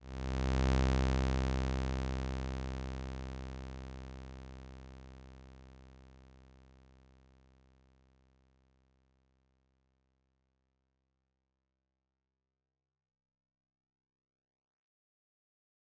ゆっくり減衰していくノコギリ波Saw波のスペクトログラム：倍音がたくさんあるので、グラフの全面が埋まる。高周波の含有量は少なくなる。
P-ENV-spectrogram-saw.mp3